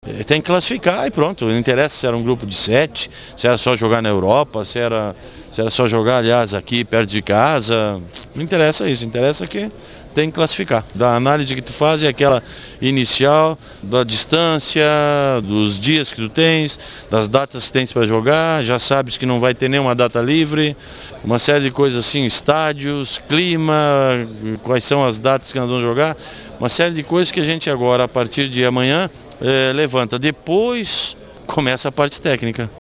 Portugal caiu no Grupo A das eliminatórias para o EURO 2008 e o técnico Luiz Felipe Scolari faz uma primeira análise.